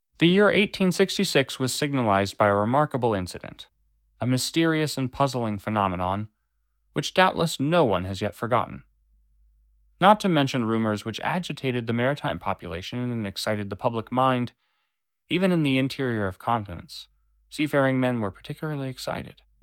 至少在我们的耳朵上，混合模型产生了稍微抛光的音频，具有讽刺意味的是，它在某种程度上消除了克隆声音的真实性。
AI使用混合模型生成音频：